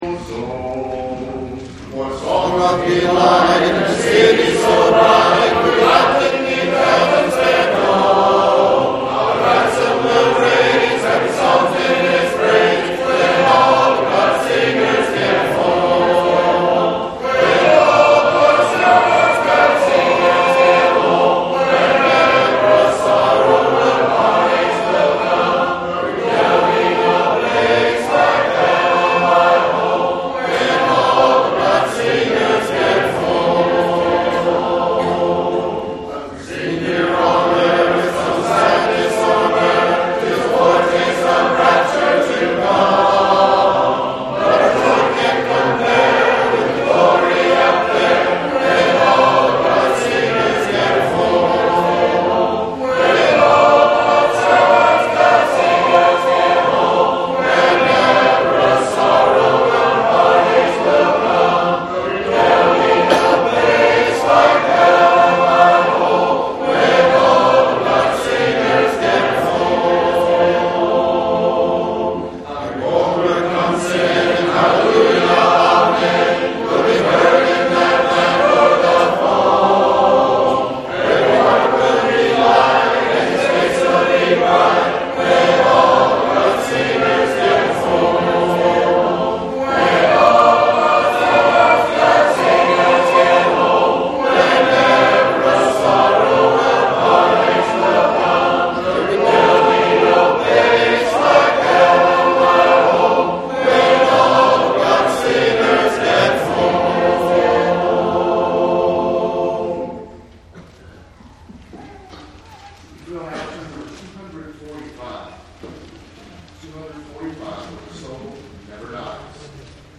Sunday Night Singing - 11/24/13 — Oak Mountain Church of Christ
SundaySinging.mp3